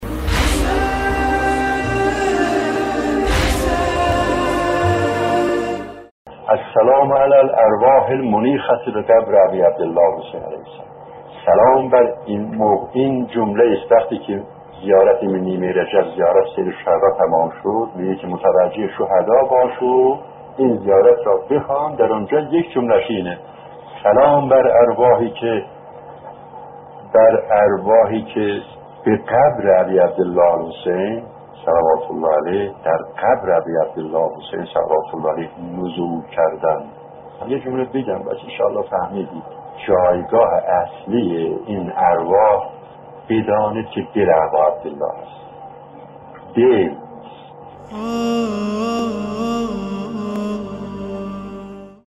منظور ارواح یاران با وفای امام حسین(ع) است و جایگاه اصلی آنها قلب سیدالشهدا(ع) است. ایکنا به مناسبت ایام سوگواری امام حسین(ع) و یاران با وفایشان، مجموعه شنیداری «چراغ هدایت» را براساس سخنرانی‌ها و کتاب چراغ هدایت نوشته مرحوم آیت‌الله محمد شجاعی منتشر می‌کند.